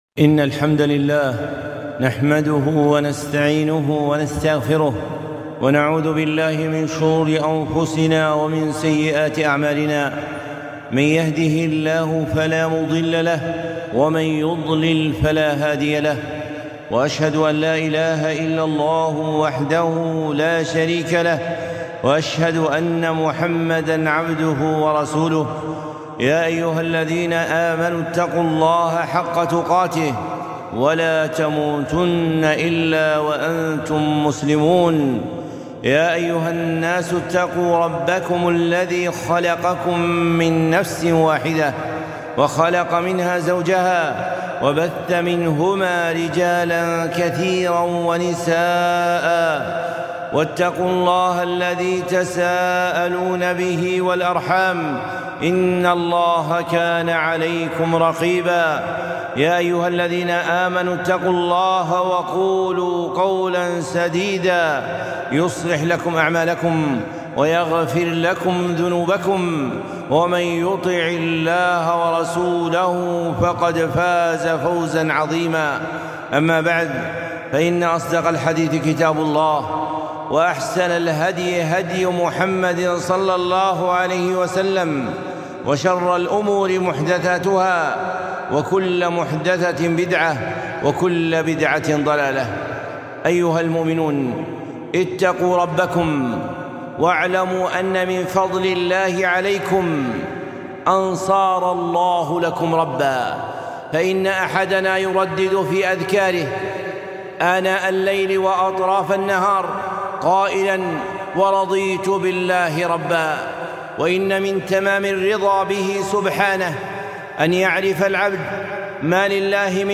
خطبة - فضل الله في عشر ذي الحجة 1443